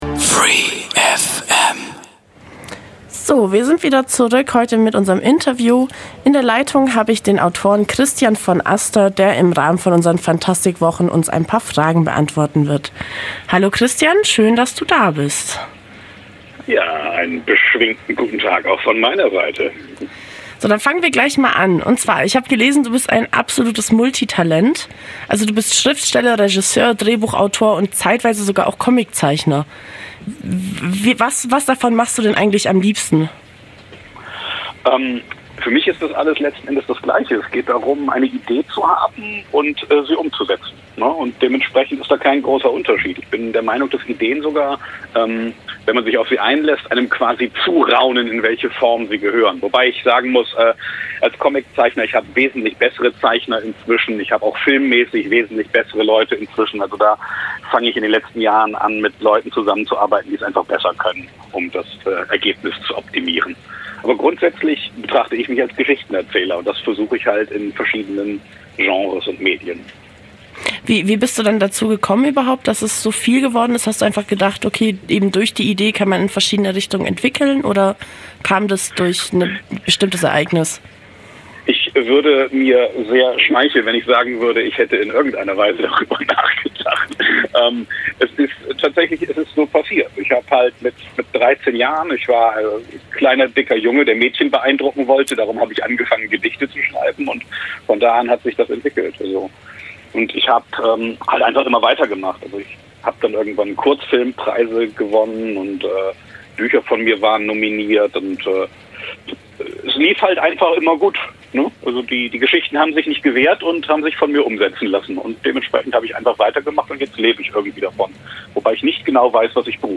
Phantastisch ging es auch heute bei uns im Studio zu.